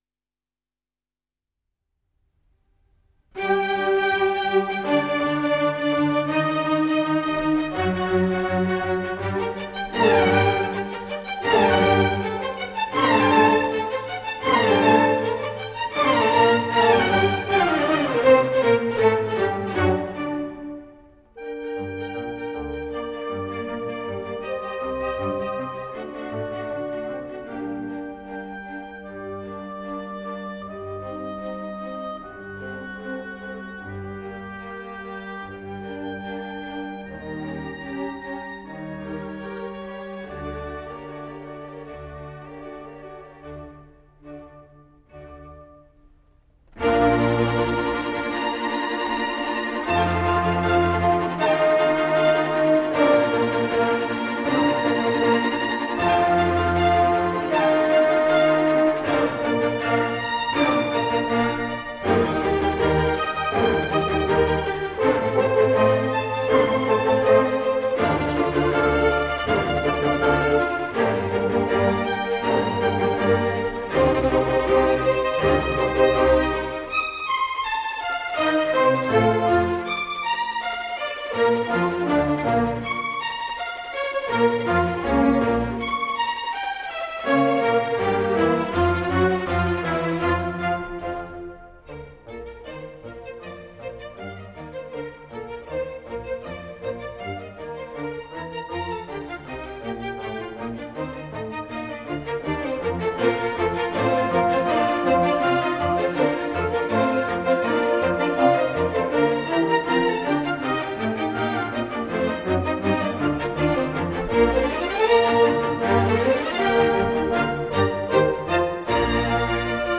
» nhac-khong-loi
» 1 - Symphonies